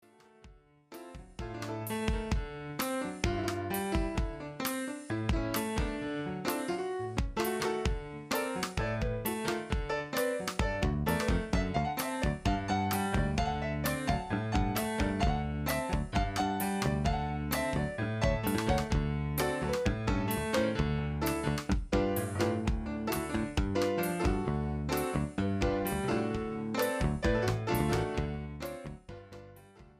(KARAOKE)  (Folk)